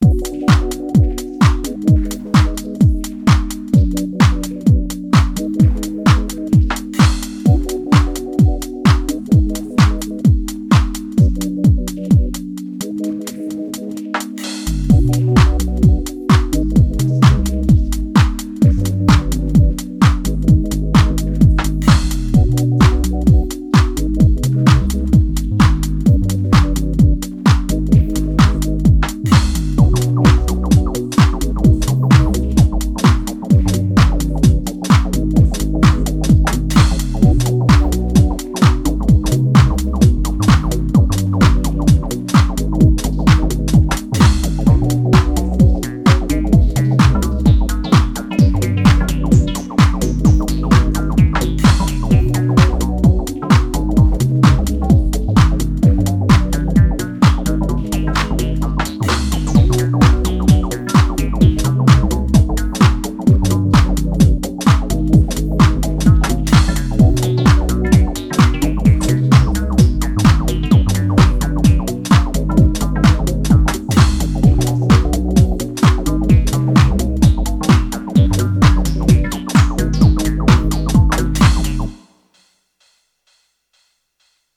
today’s alienwarez, all basses are A4 (MD drum synths on drum duties, DN2 chords)